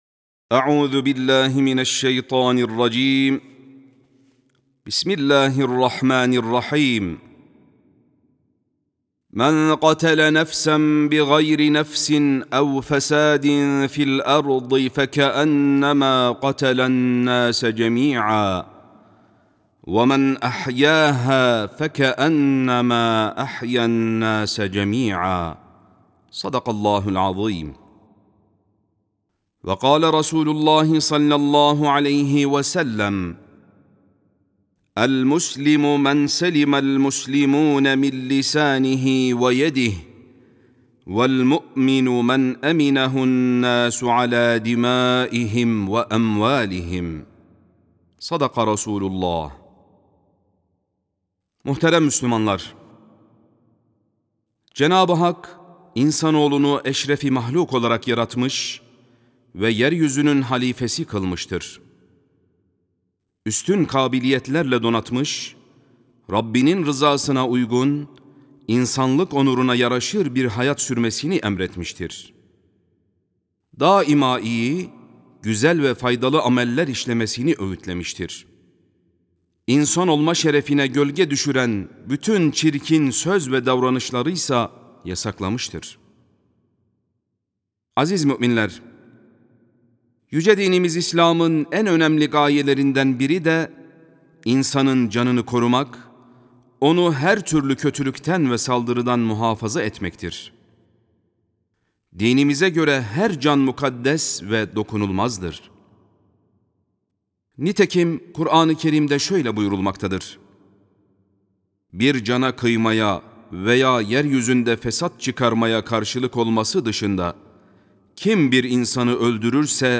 22.07.2022 Tarihli Cuma Hutbesi
Sesli Hutbe (Her Can Mukaddestir).mp3